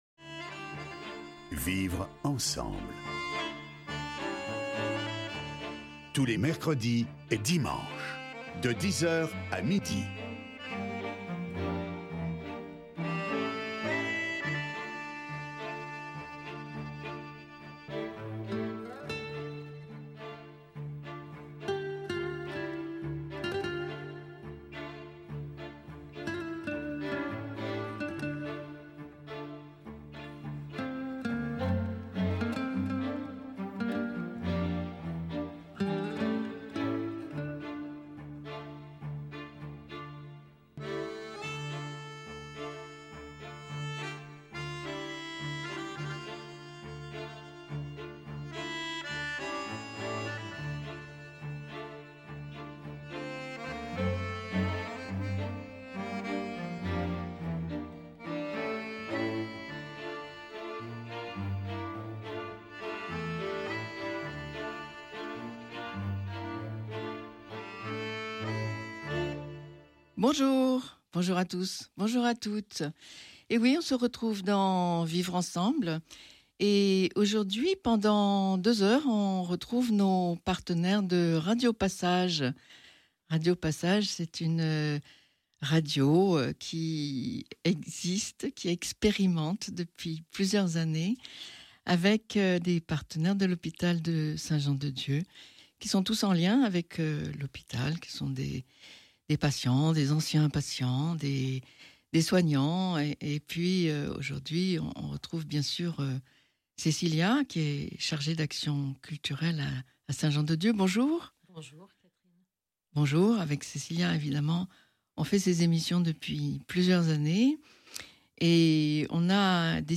D’avril à juin, un nouveau cycle de travail a permis de préparer une émission sur le thème des « transports ». Aller à la rencontre des gens dans la rue, parler de notre plaisir ou de nos difficultés à prendre un bus, un tram, un train… écouter leur bruit, sentir les ambiances.
Le mercredi 18 juin, du studio de Radio Pluriel à Saint-Priest, nous jouons collectivement la « pièce ». Entre improvisation et partition précise, l’émission se déroule.